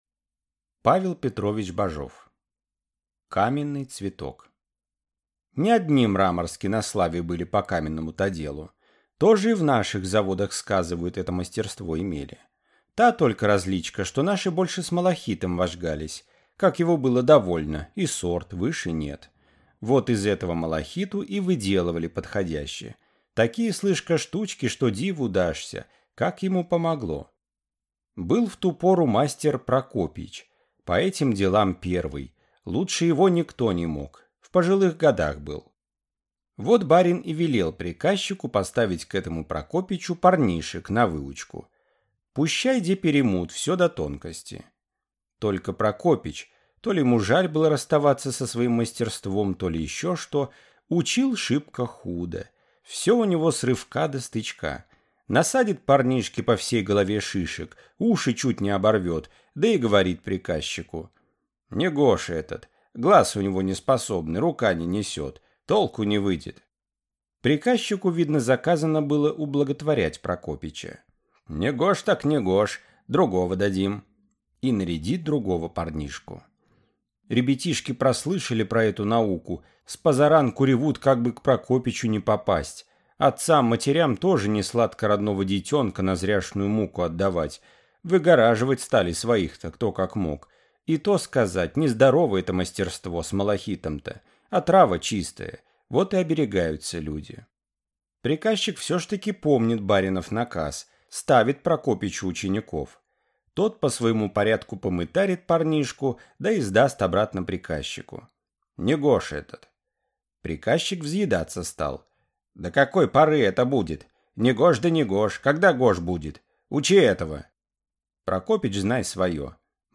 Аудиокнига Каменный цветок | Библиотека аудиокниг